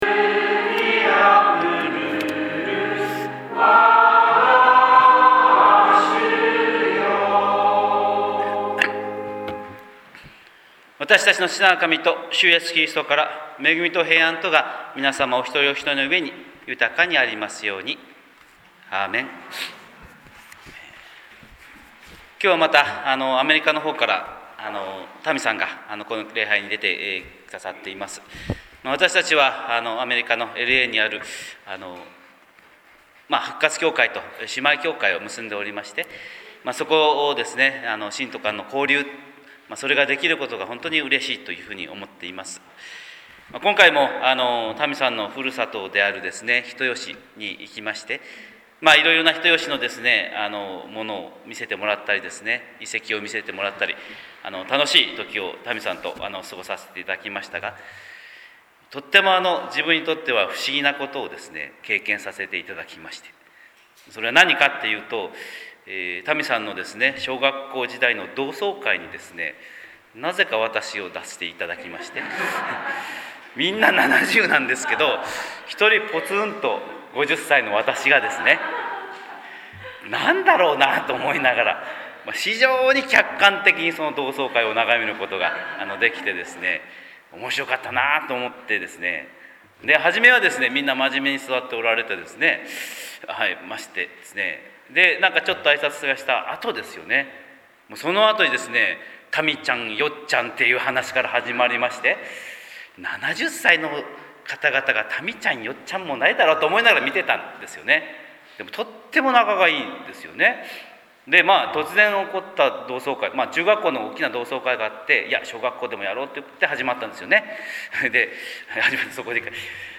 神様の色鉛筆（音声説教）
朝礼拝140530